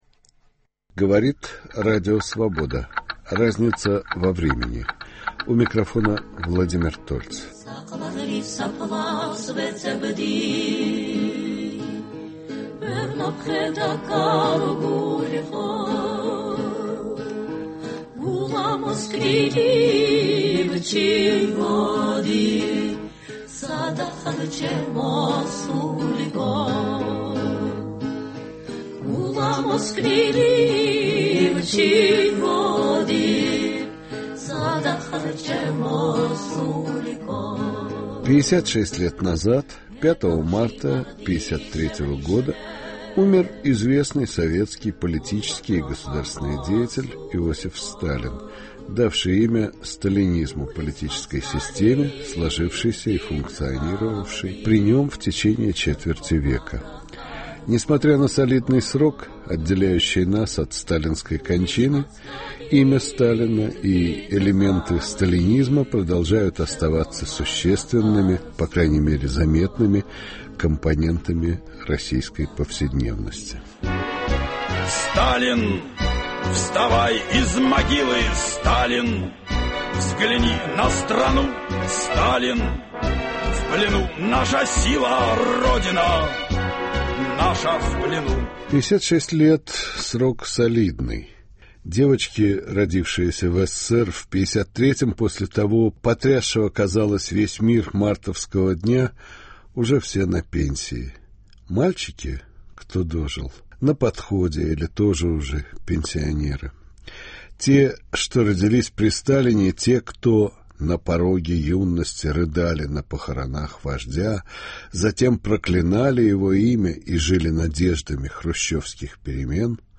беседует с актером и режиссером Сергеем Юрским